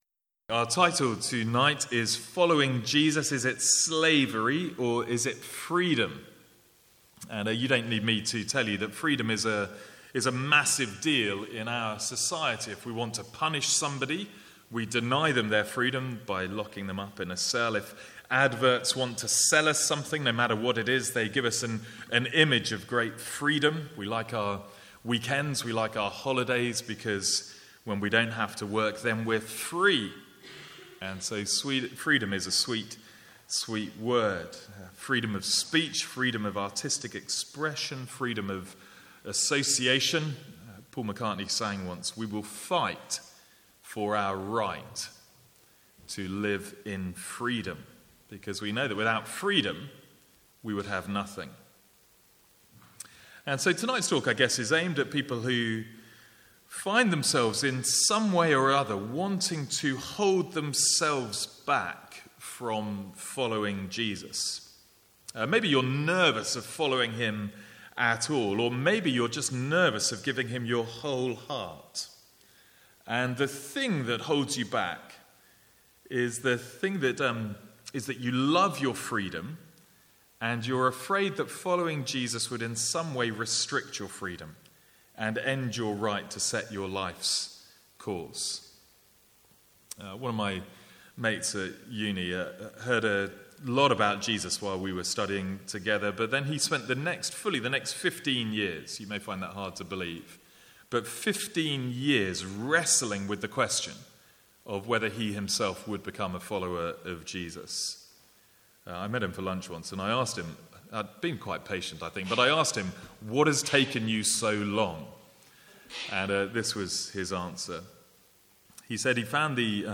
From the Sunday evening 'Tough Questions' guest series (with Q&A).